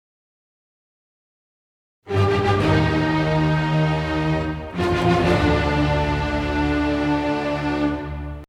The opening of the fifth symphony is a famous example of a theme that is clearly NOT emotionally neutral: